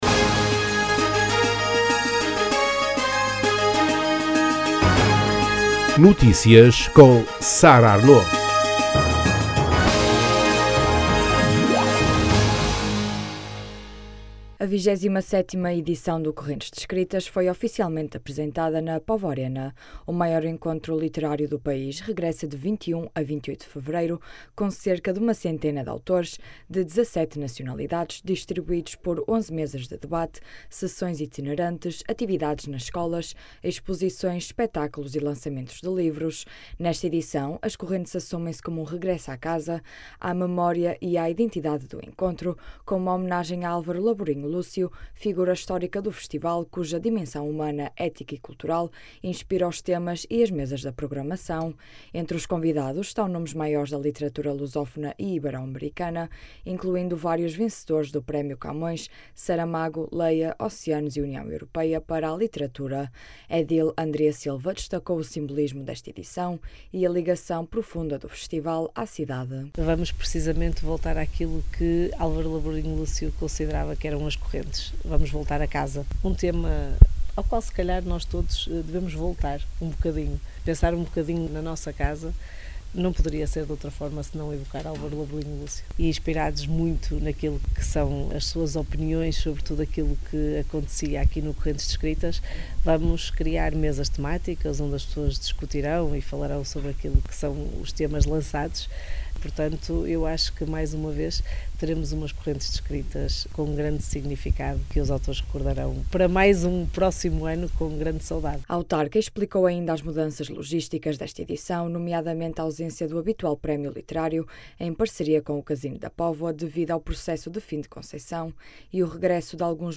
Correntes regressa à 'casa' com homenagem a Laborinho Lúcio Detalhes Categoria: Notícias Regionais Publicado em quarta-feira, 11 fevereiro 2026 09:26 Escrito por: Redação A 27ª edição do Correntes d’Escritas foi oficialmente apresentada no Póvoa Arena.
A edil Andrea Silva destacou o simbolismo desta edição e a ligação profunda do festival à cidade. A autarca explicou ainda as mudanças logísticas desta edição, nomeadamente a ausência do habitual prémio literário em parceria com o Casino da Póvoa, devido ao processo de fim de concessão, e o regresso de alguns momentos do programa a espaços históricos da cidade.